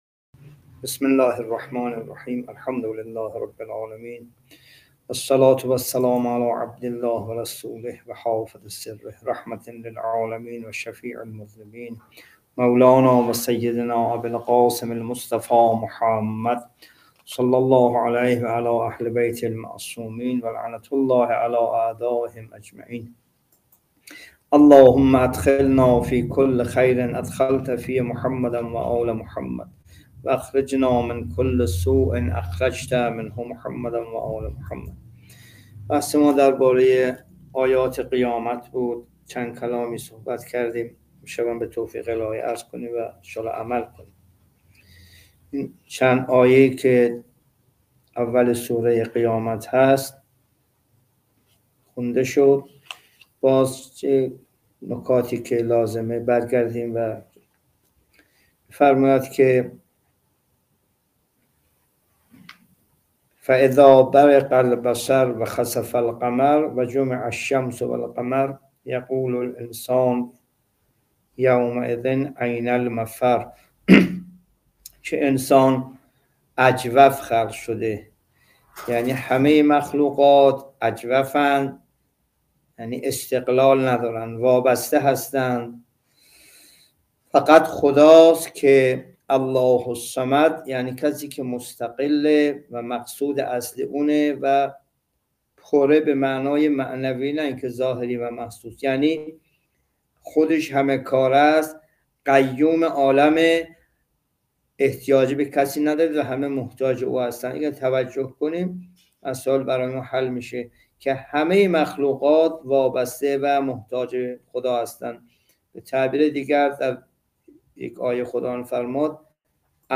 جلسه تفسیر قرآن(4) سوره قیامت